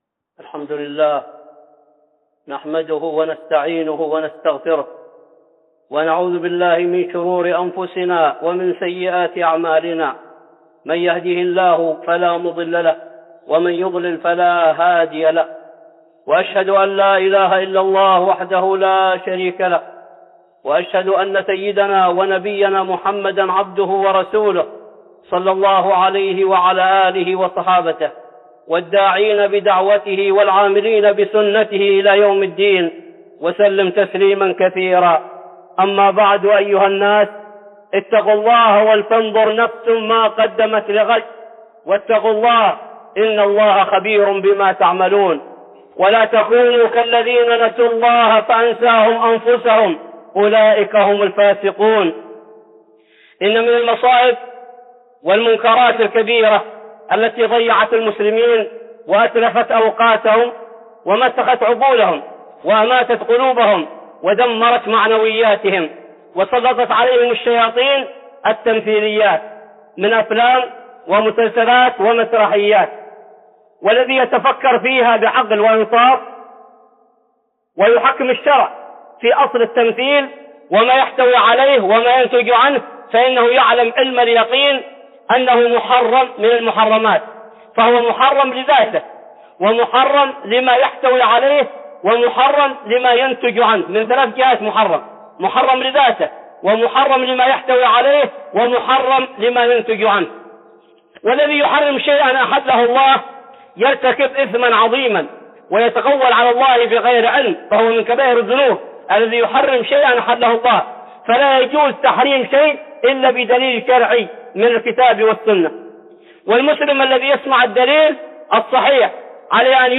(خطبة جمعة) تحريم التمثيل